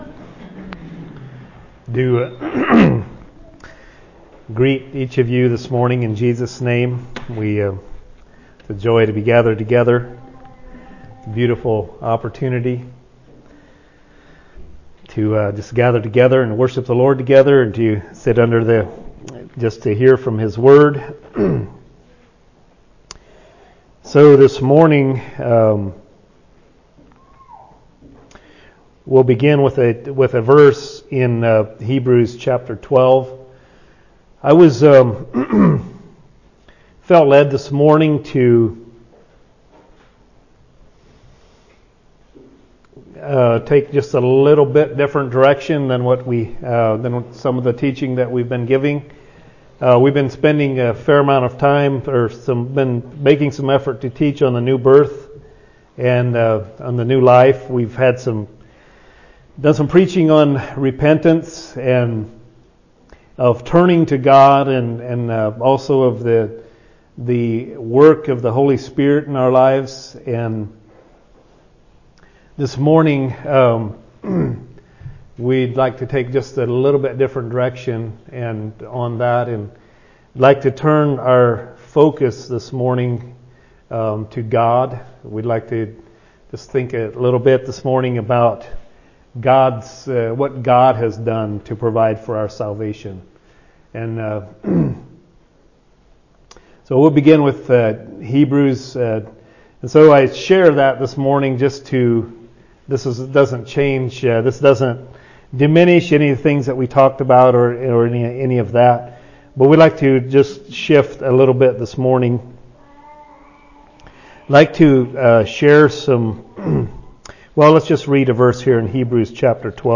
A message from the series "2025 Messages."